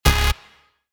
Vortex_OS_Stabs_7_C#
Vortex_OS_Stabs_7_C.mp3